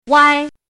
“歪”读音
wāi
歪字注音：ㄨㄞ
国际音标：wĄi˥
wāi.mp3